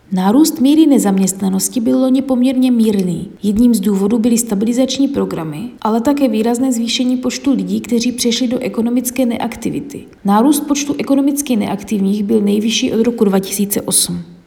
Vyjádření Marka Rojíčka, předsedy ČSÚ, soubor ve formátu MP3, 534.74 kB